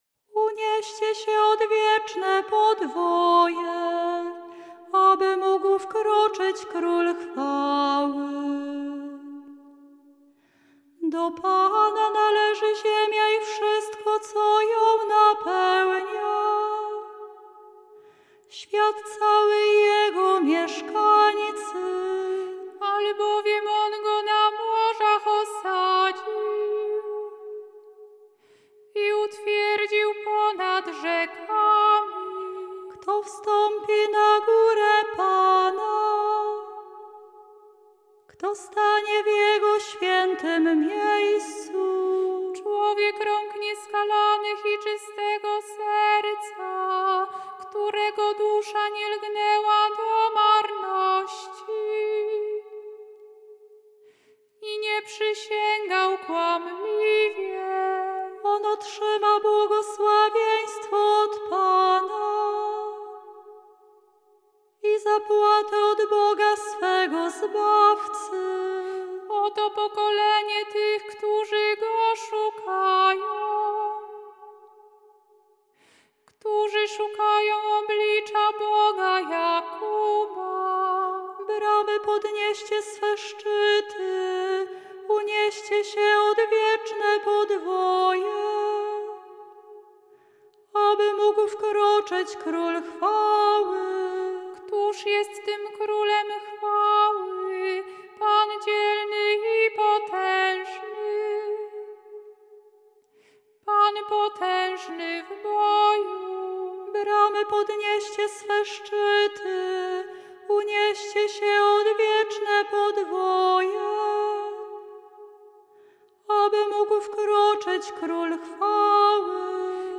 Dlatego do psalmów zastosowano tradycyjne melodie tonów gregoriańskich z ich różnymi, często mniej znanymi formułami kadencyjnymi (tzw. dyferencjami).
Dla pragnących przygotować się do animacji i godnego przeżycia tych wydarzeń liturgicznych przedstawiamy muzyczne opracowanie poszczególnych części wykonane przez nasze siostry